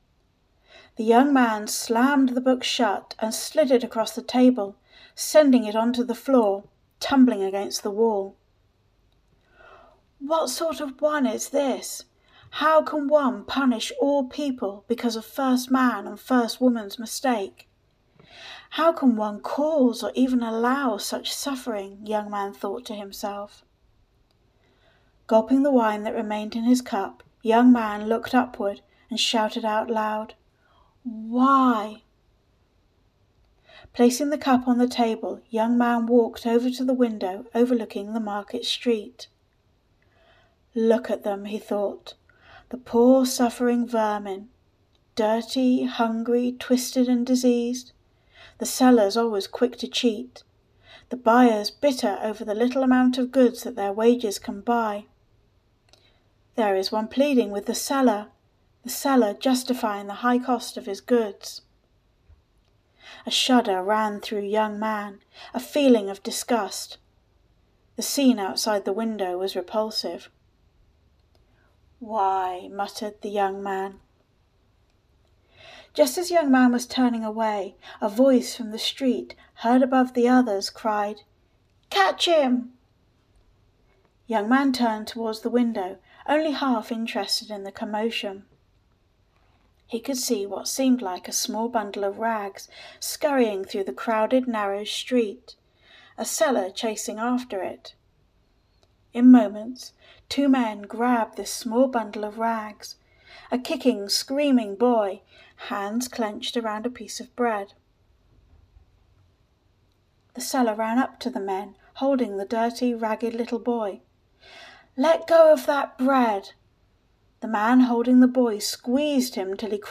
The Young Man and the Old Man - Audiobook